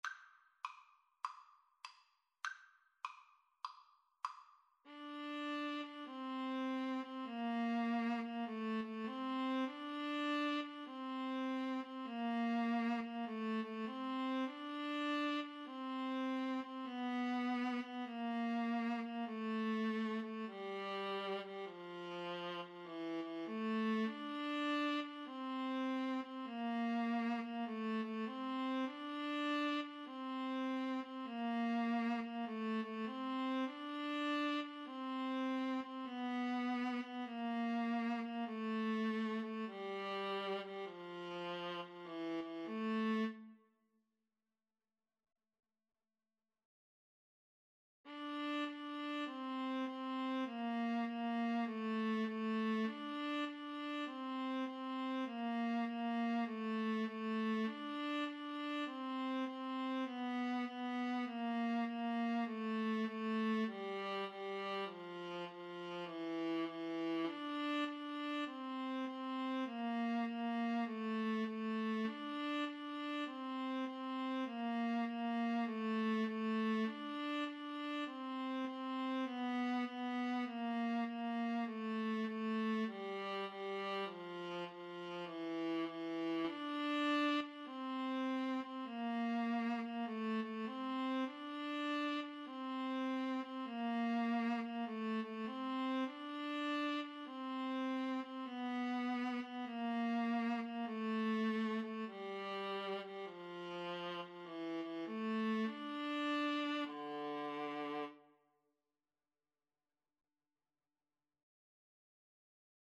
4/4 (View more 4/4 Music)
Jazz (View more Jazz Violin-Viola Duet Music)